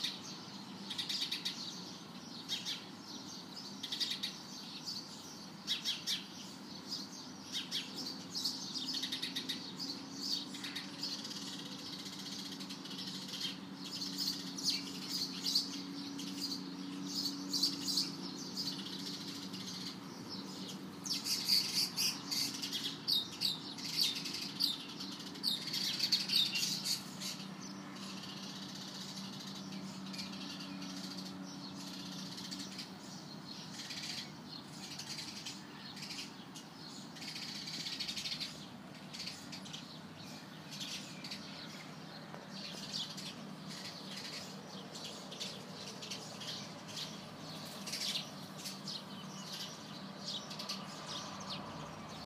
The birds on my window sill and on the tree neighbouring my window:
chirping-birds.m4a